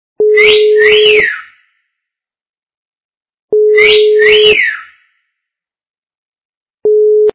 При прослушивании Мужской - свист качество понижено и присутствуют гудки.
Звук Мужской - свист